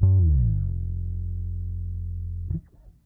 bass1.wav